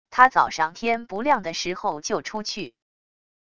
他早上天不亮的时候就出去wav音频生成系统WAV Audio Player